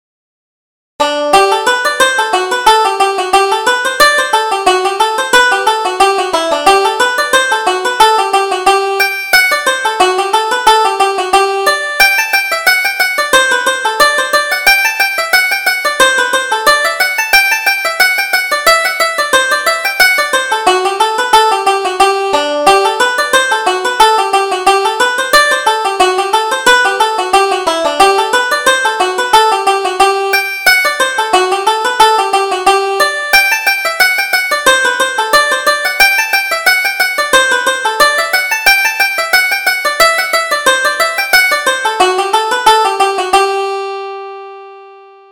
Reel: Annie O'Neill